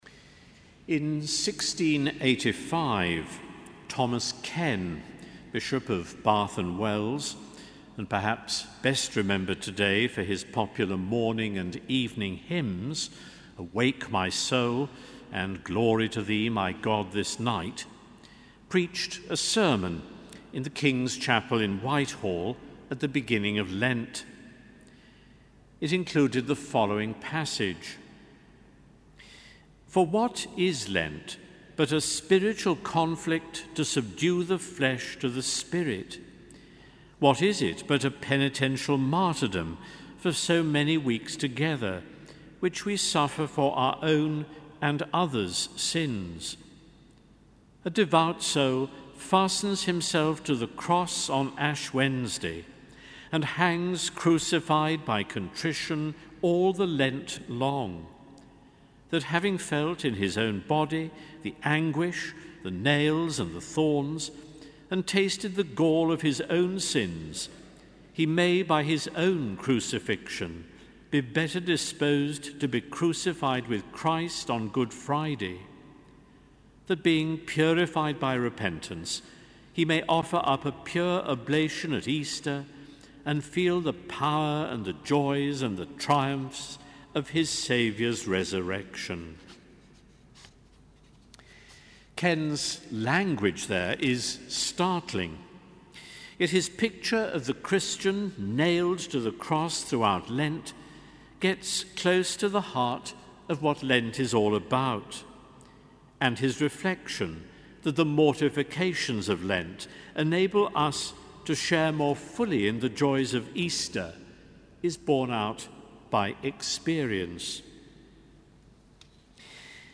Sermon: Evensong 9 Mar 2014